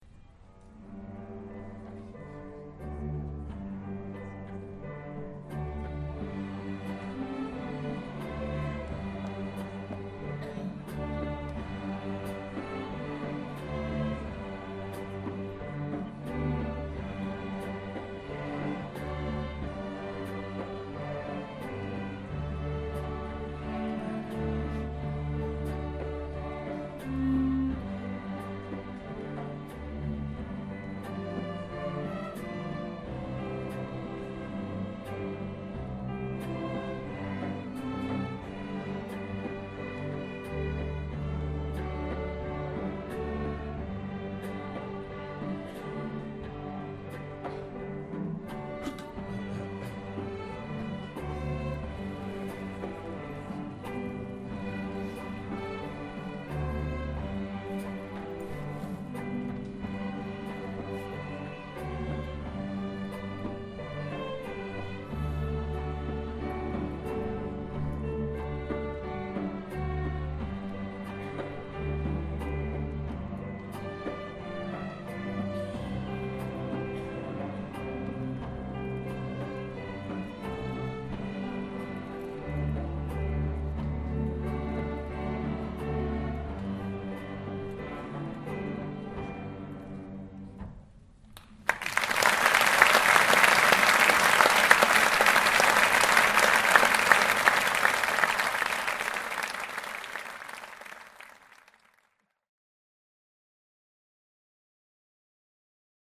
Sloop John B - Funky Strings